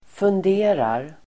Uttal: [fund'e:rar]